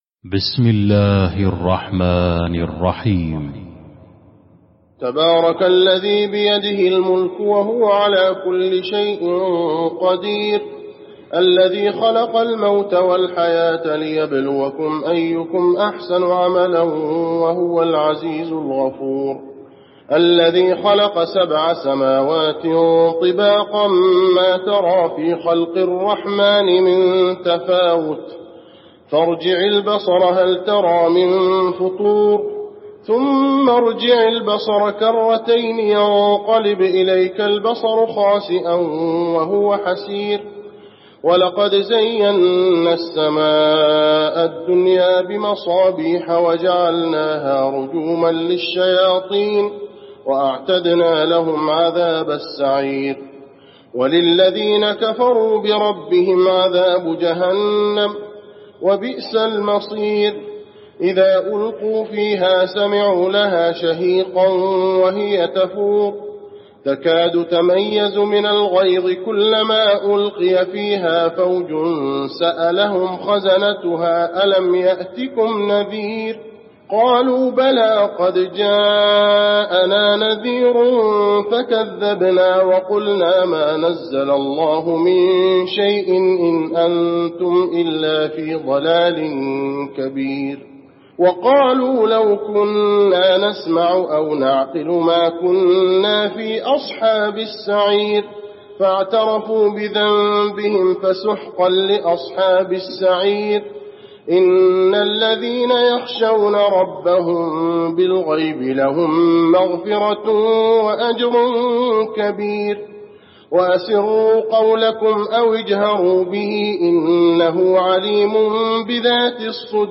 المكان: المسجد النبوي الملك